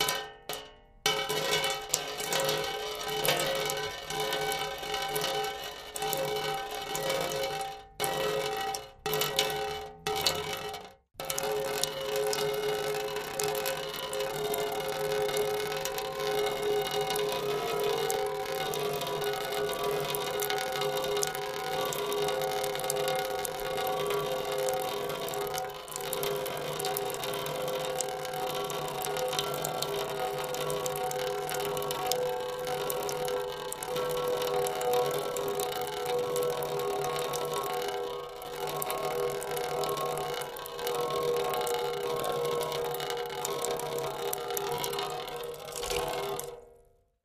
Water Trickle On A Metal Pot x2